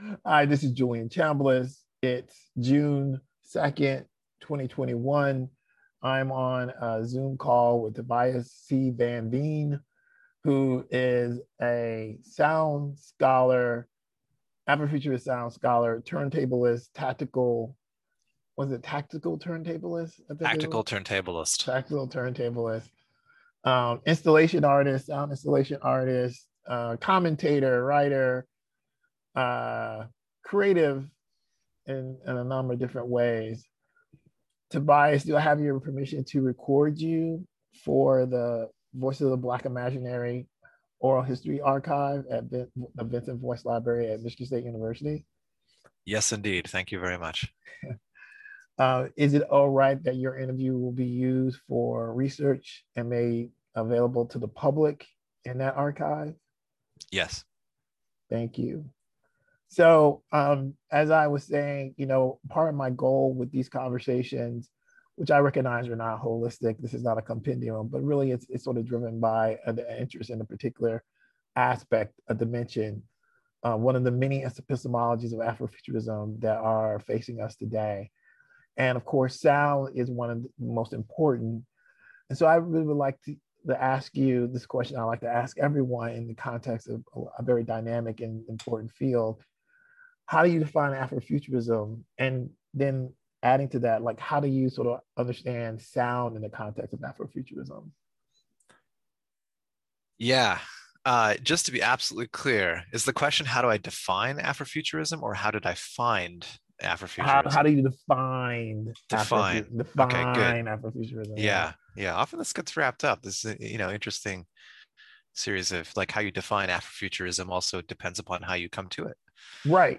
Interview
Interviews